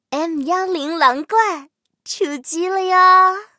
M10狼獾出击语音.OGG